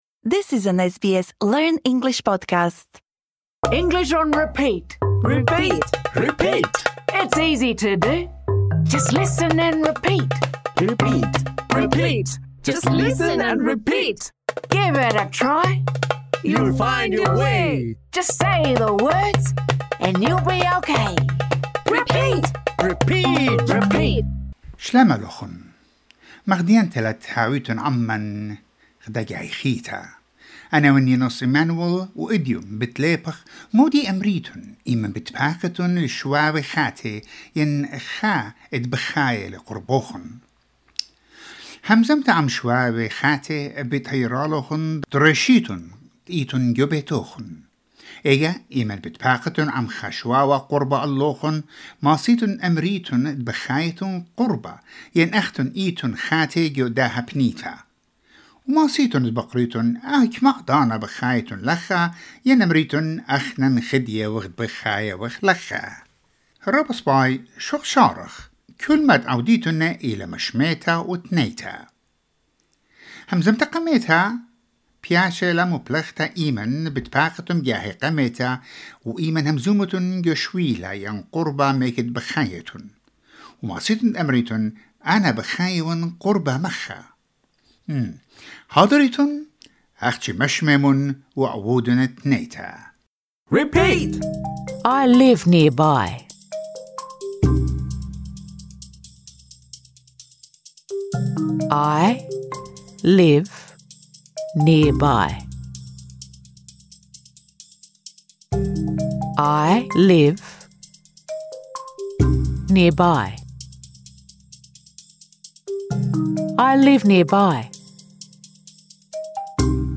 This lesson is designed for easy-level learners.